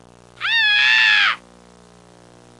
Scream Sound Effect
Download a high-quality scream sound effect.
scream-3.mp3